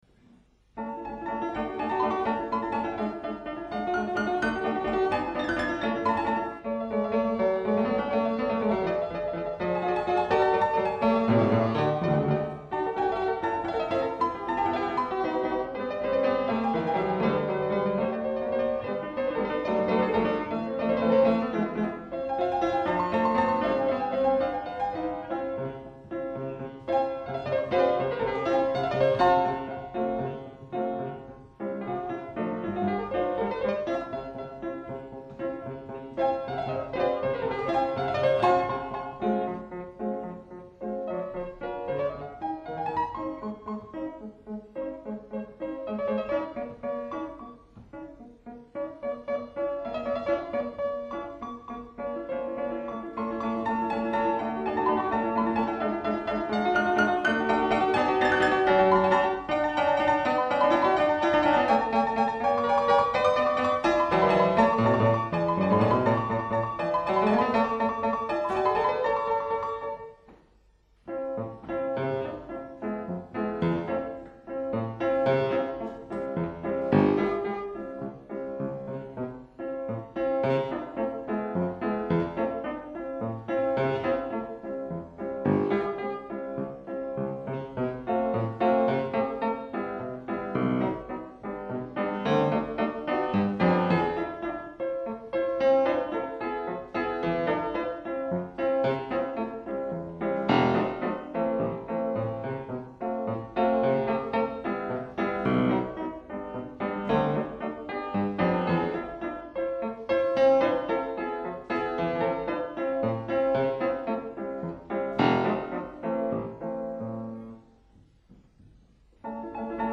Le troisième est ternaire comme il se doit, le quatrième modérément rapide.